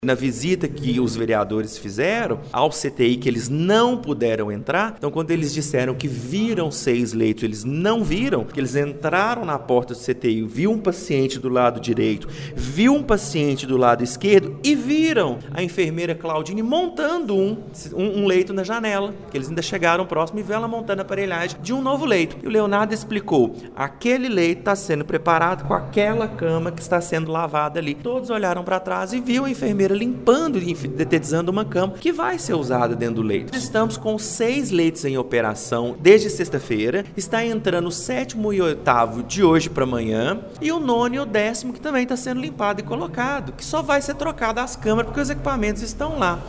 Paulo Duarte, secretário municipal de Saúde, explicou que o novo CTI entrou em operação no dia da inauguração.